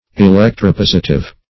Electro-positive \E*lec`tro-pos"i*tive\, a.